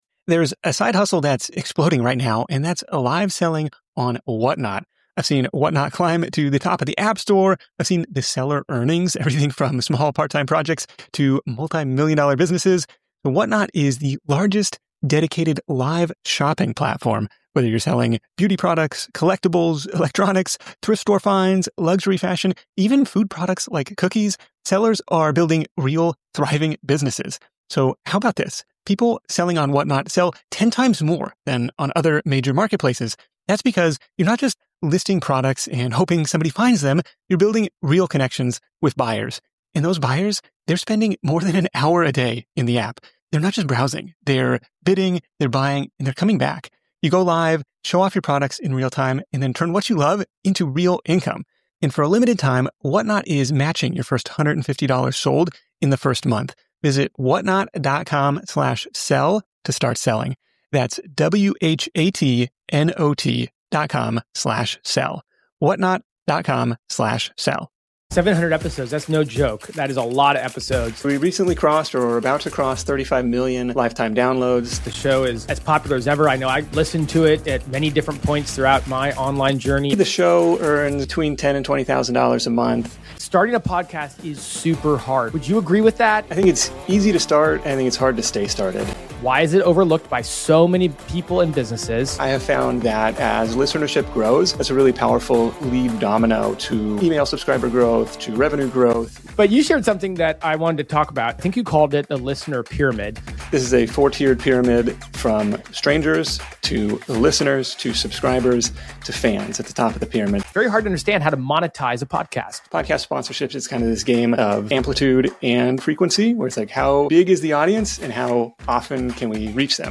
In this week’s episode, I sit down with the Niche Pursuits Podcast to discuss what it takes to build a successful podcast from the ground up.